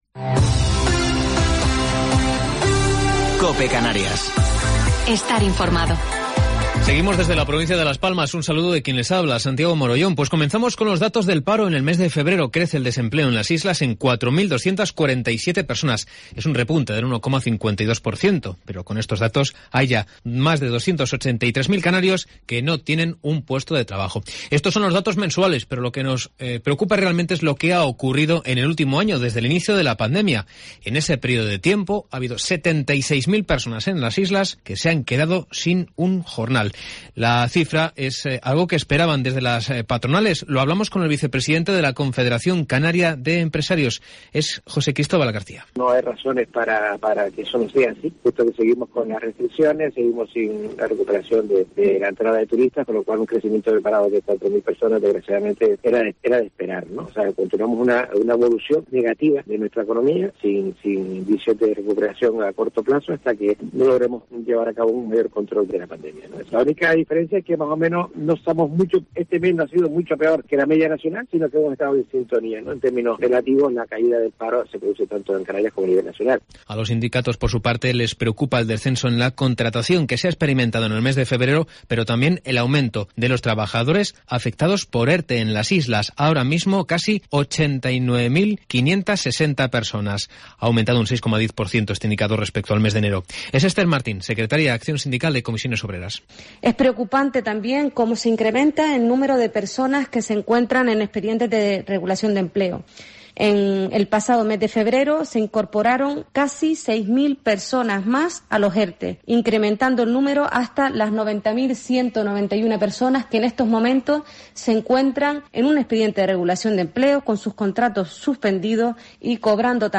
Informativo local 2 de Marzo del 2021